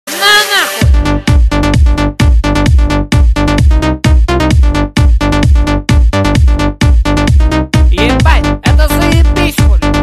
громкие
энергичные
electro house